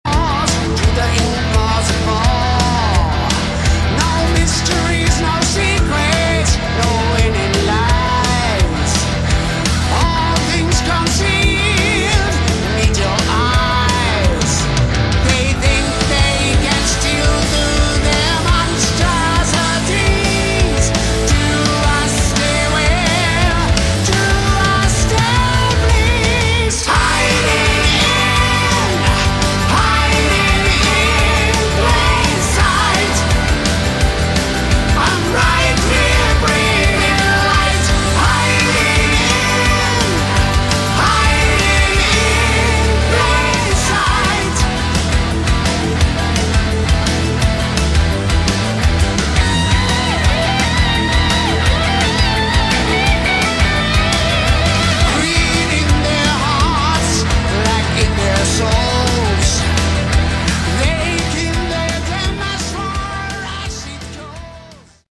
Category: Hard Rock
lead and backing vocals
guitars, keys, backing vocals
drums
bass